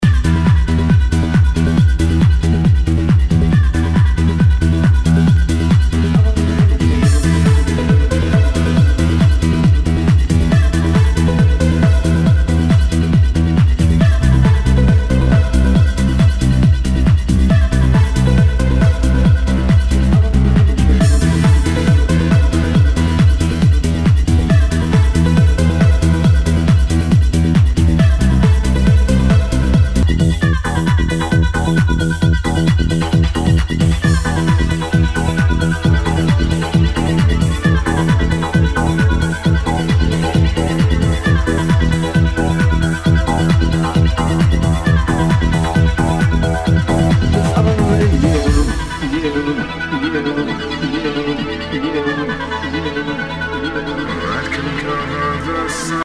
HOUSE/TECHNO/ELECTRO
ナイス！プログレッシブ・ハウス！